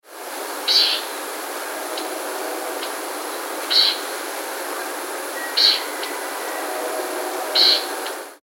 a. A harsh long drawn-out buzz (U).
A rasping scold, harsh loud chattering ratchet, pdddd; louder than Wrentit's chatter.
Example 1 (given as a montone) ... example 2 (upslurred) ...
example 3 (shorter, sounds 'clipped').
81 Bewick's Wren buzzes.mp3